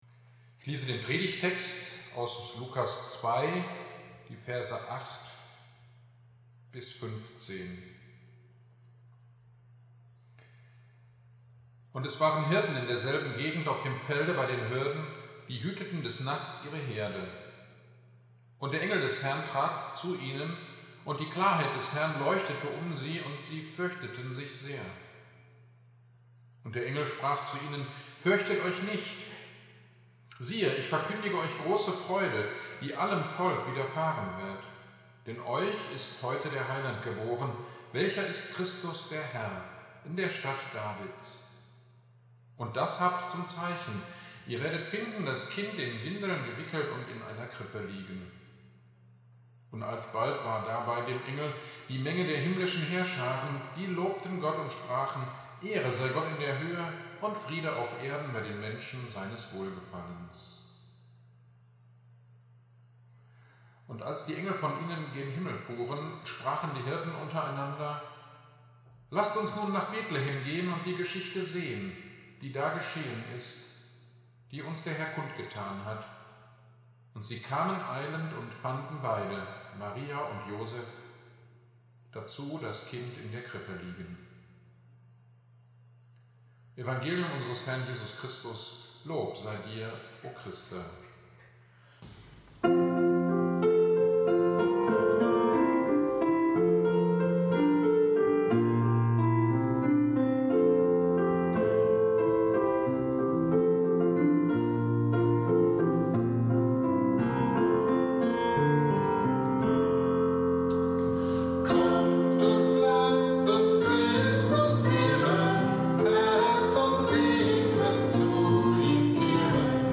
Predigt zum Christfest 2020 - Lukas 2,8-16 - Kirchgemeinde Pölzig